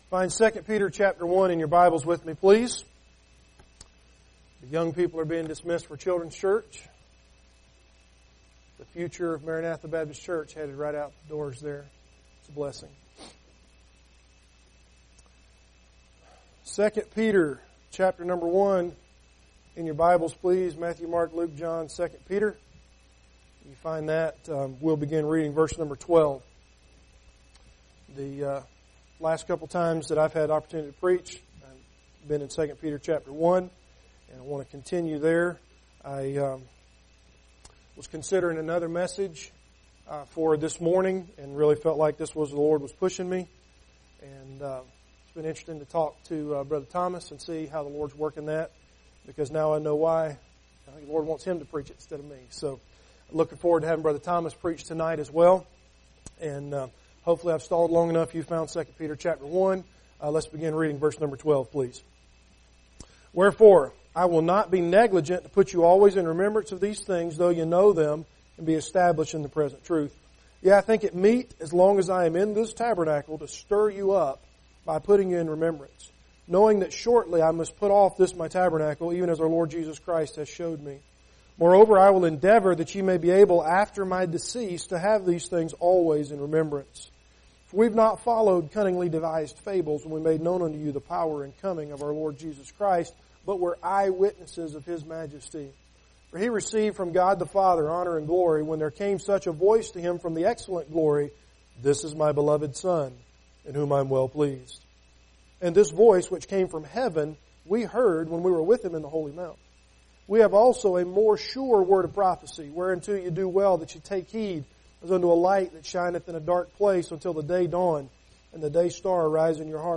- Maranatha Baptist Church